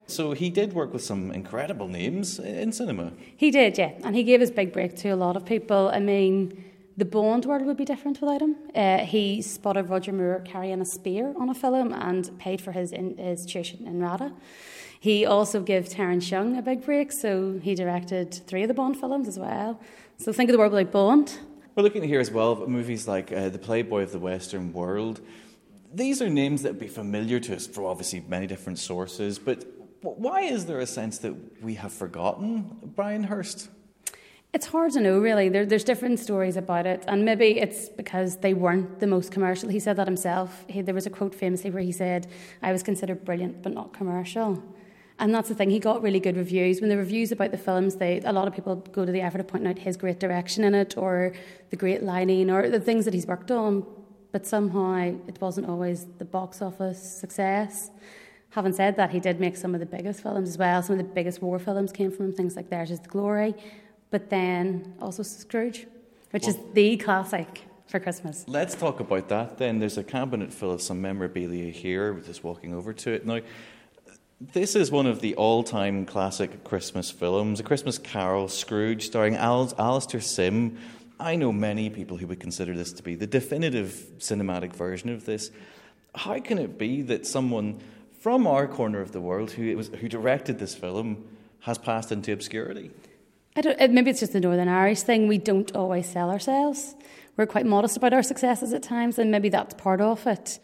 at a exhibition for the director